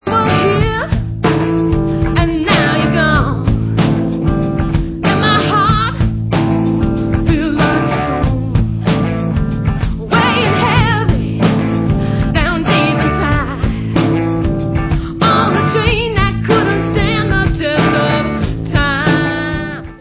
rocking-soul side-project
sledovat novinky v kategorii Rock